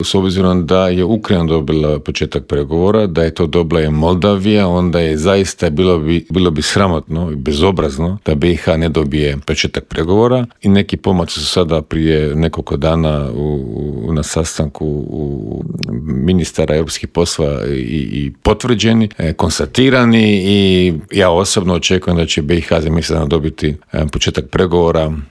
ZAGREB - Uoči druge godišnjice početka ruske agresije na Ukrajinu, u Intervjuu Media servisa razgovarali smo s bivšim ministrom vanjskih poslova Mirom Kovačem, koji nam je kratko proanalizirao trenutno stanje u Ukrajini, odgovorio na pitanje nazire li se kraj ratu, a osvrnuo se i na izbor novog glavnog tajnika NATO saveza i na nadolazeći sastanak Europskog vijeća.